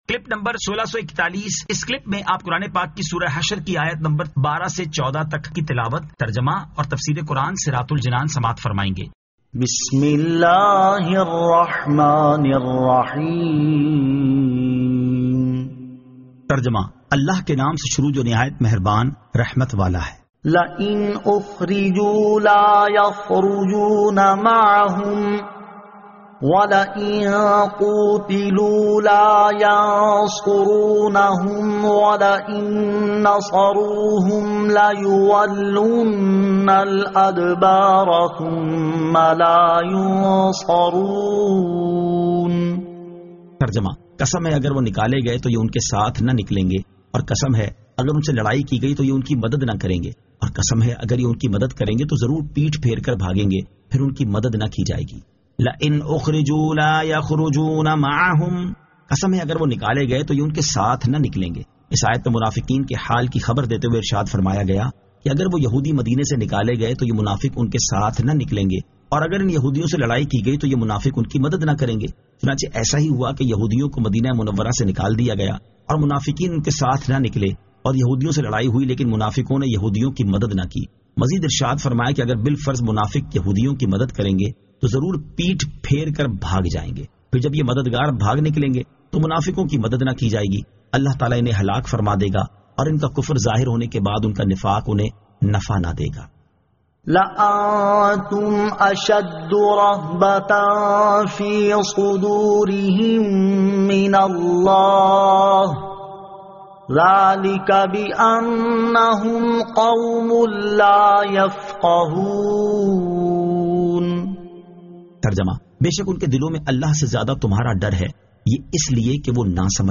Surah Al-Hashr 12 To 14 Tilawat , Tarjama , Tafseer
2024 MP3 MP4 MP4 Share سُوَّرۃُ الحَشَرٗ آیت 12 تا 14 تلاوت ، ترجمہ ، تفسیر ۔